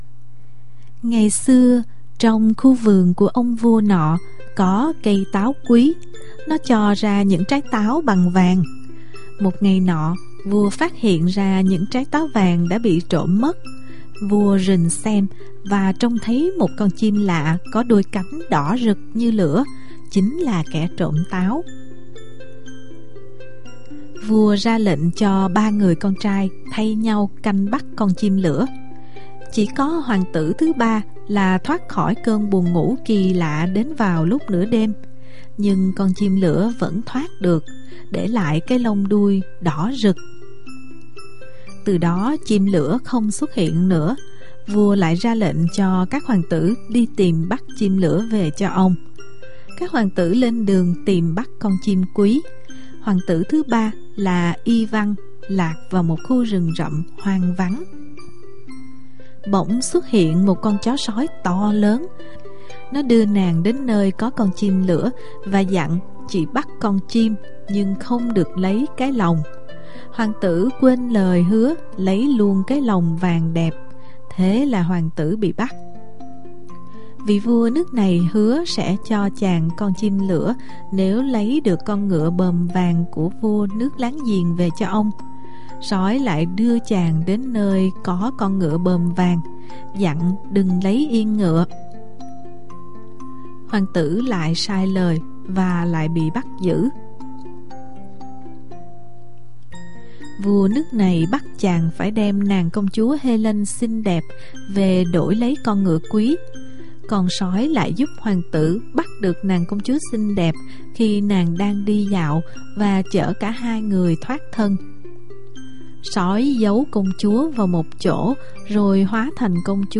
Sách nói | HOÀNG TỬ IVAN VÀ CON CHIM LỬA